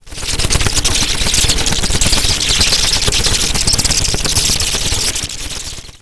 bats_mono_oneshot.wav